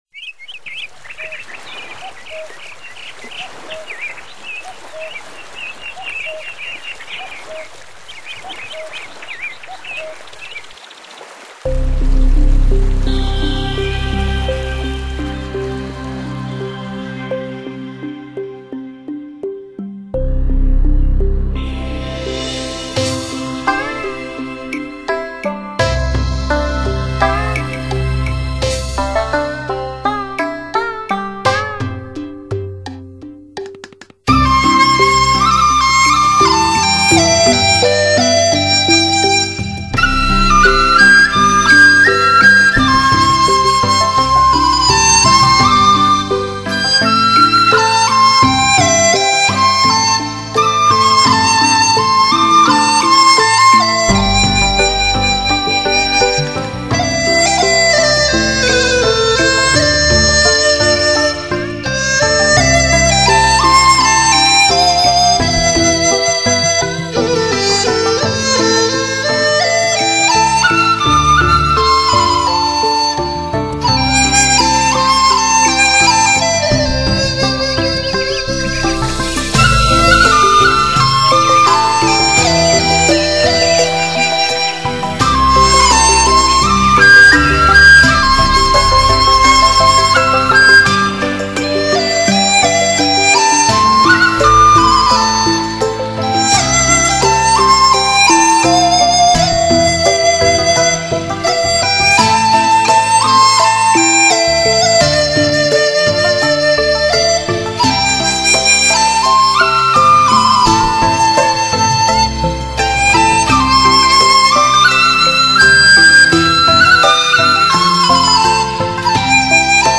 笛子